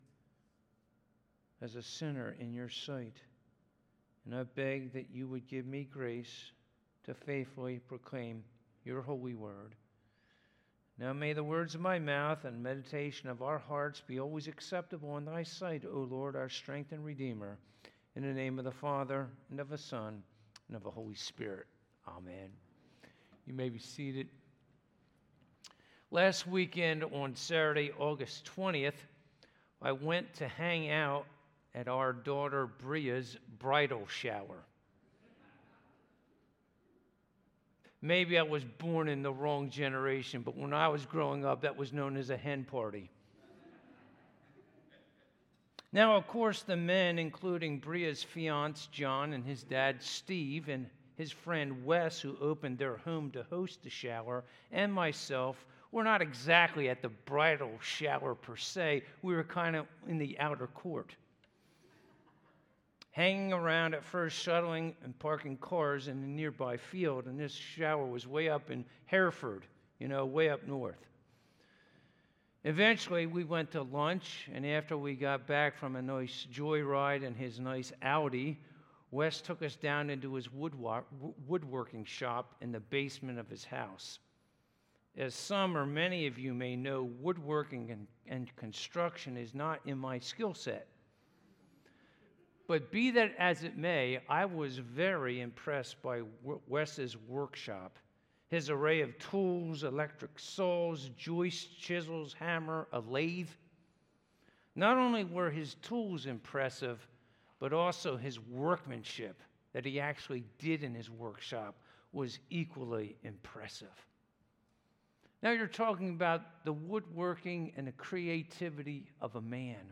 Passage: Proverbs 8:22-36 Service Type: Sunday Morning « What is the Call of Wisdom?